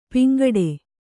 ♪ piŋgaḍe